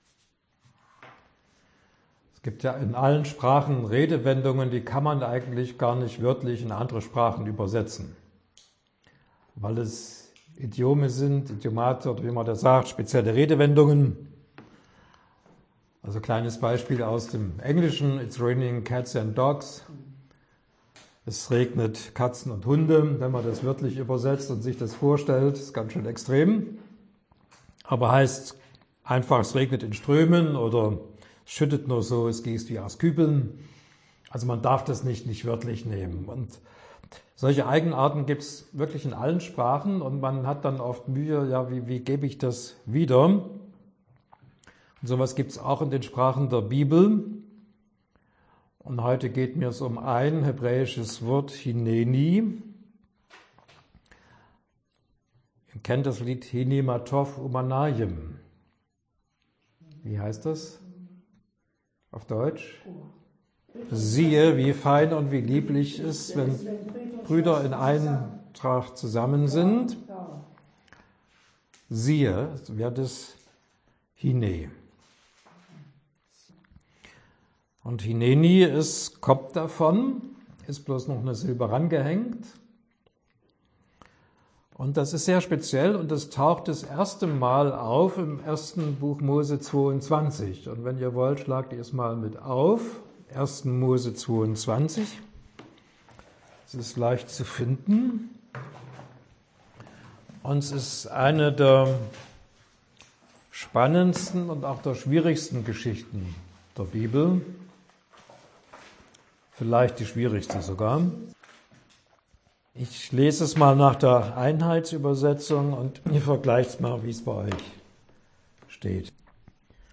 Länge des Vortrages: ca. 37 Minuten